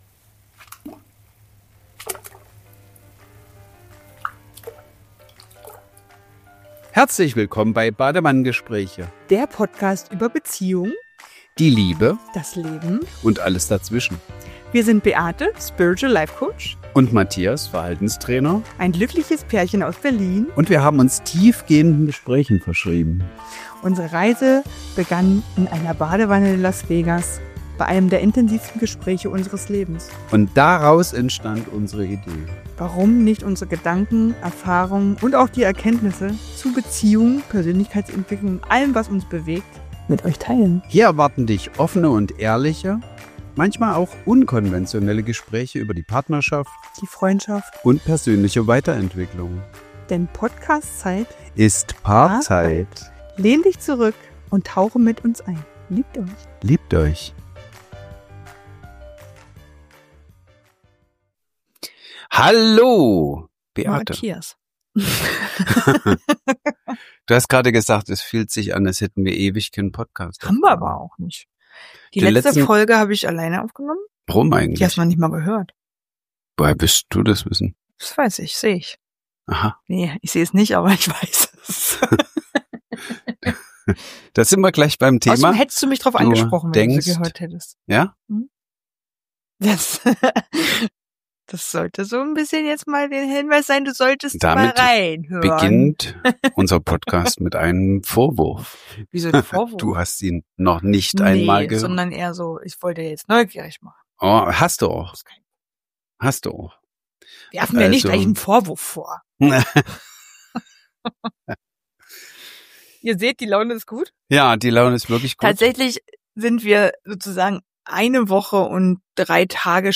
Der Raum der Stille: Eine Handpan-Klangreise – Badewannengespräche – Lyssna här – Podtail